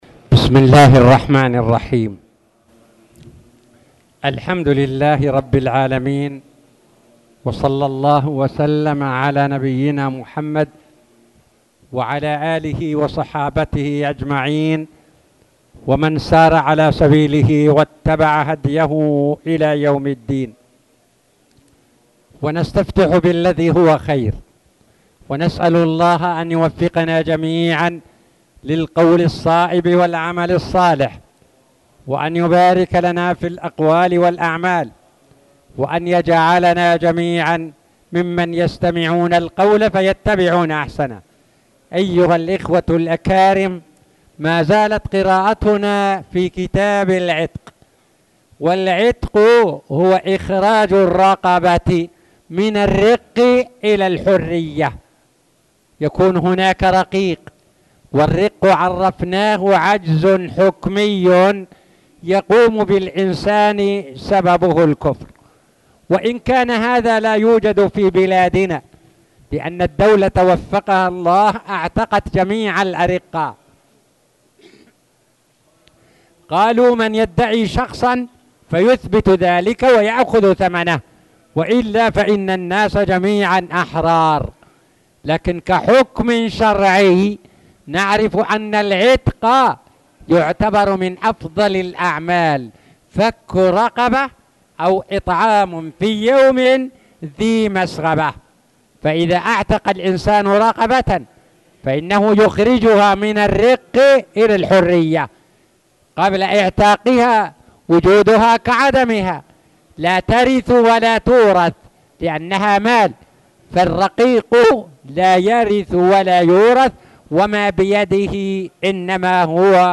تاريخ النشر ١٦ محرم ١٤٣٨ هـ المكان: المسجد الحرام الشيخ